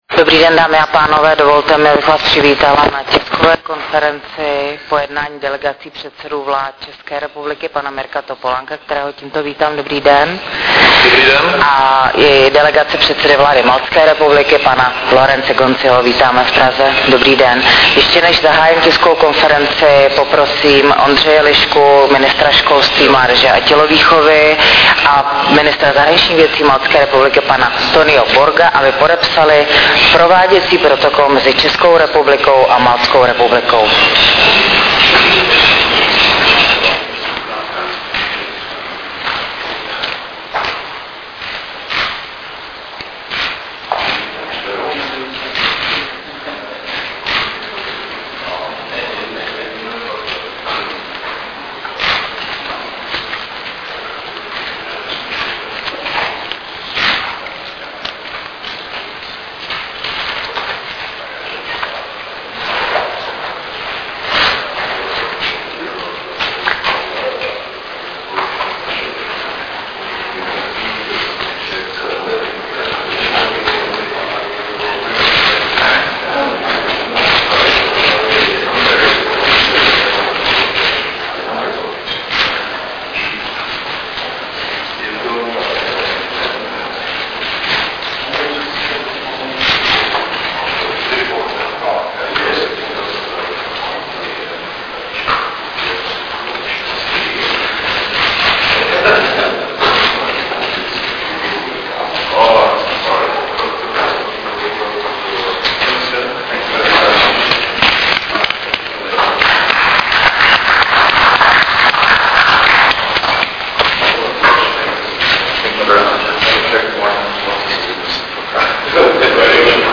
Tisková konference premiéra M. Topolánka a předsedy vlády Republiky Malta Lawrence Gonziho 9.12.2008